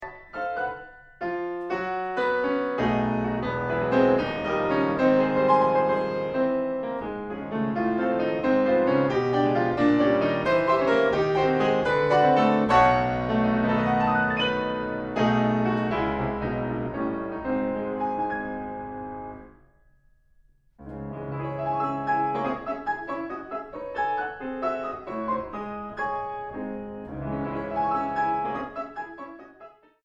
Allegretto 2.26